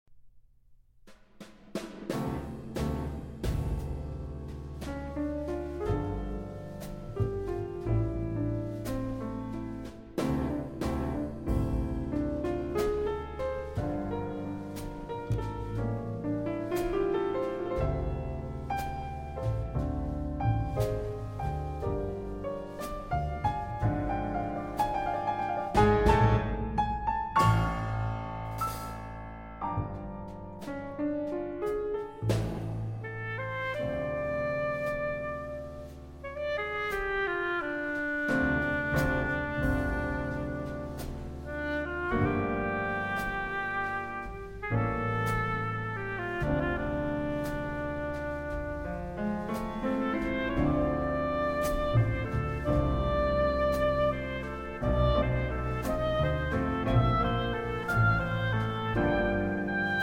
Oboe